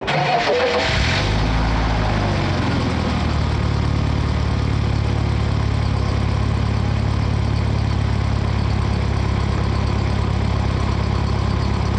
MotorAn+Laeuft.wav